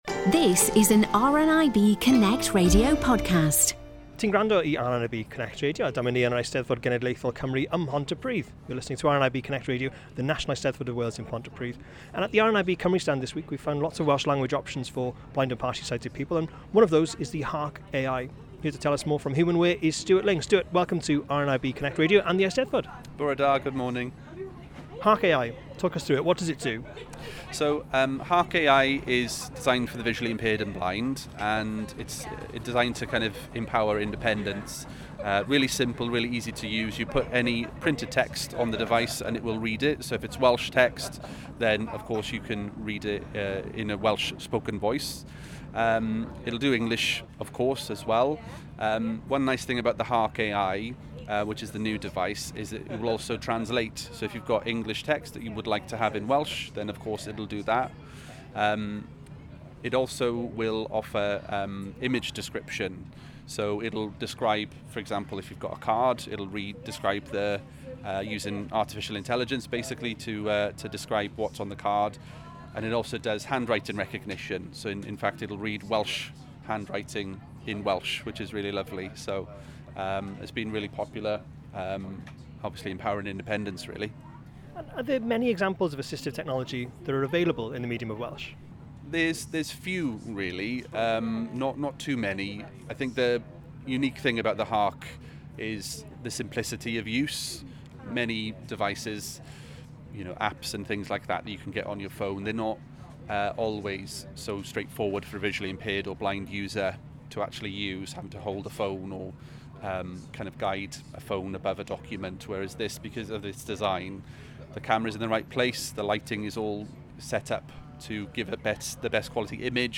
The Eisteddfod is Europe’s largest cultural festival, a week-long celebration of Welsh language and culture.